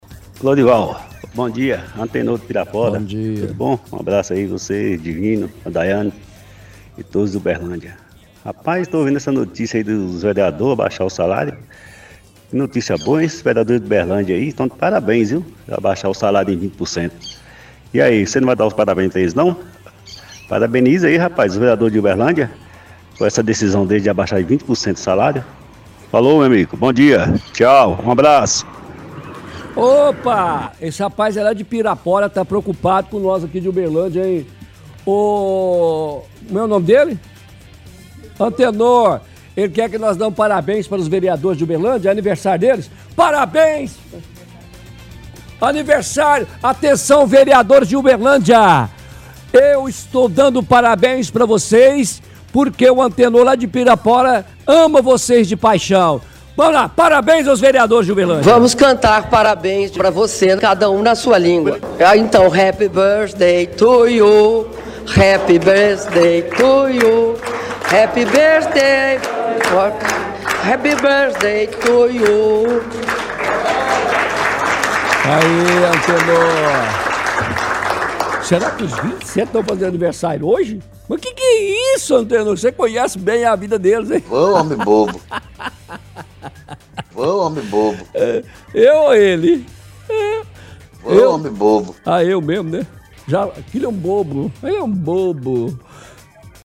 – Ouvintes parecem não entender que notícia não é de Uberlândia.